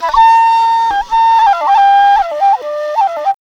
AFRIK FLUTE1.wav